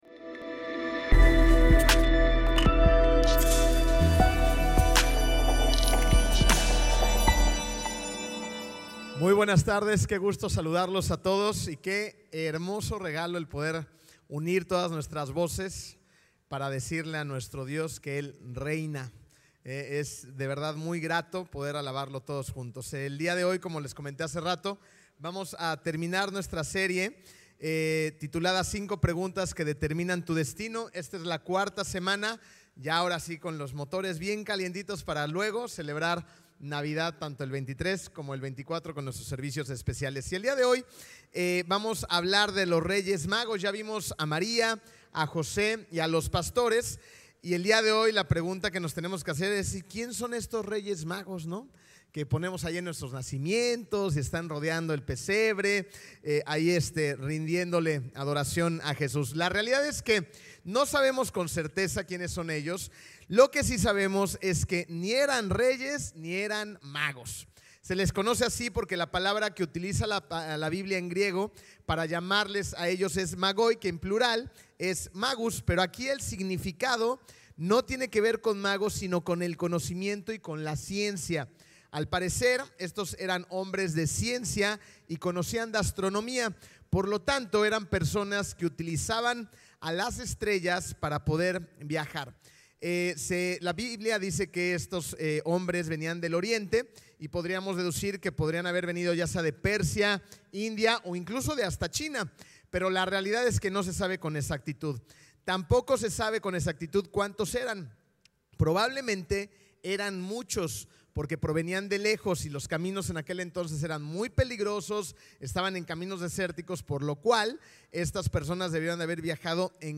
Mensajes y prédicas de Comunidad de Fe Cancún.